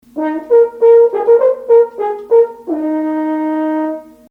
Waldhorn
Das Waldhorn, oft auch kurz „Horn“ genannt, ist ein Blechblasinstrument.
Ob kleine oder grosse Formationen, das Horn ist mit seinem weichen und warmen Ton, der aber auch schmetternd stark sein kann, sehr vielseitig einsetzbar.
Horn.mp3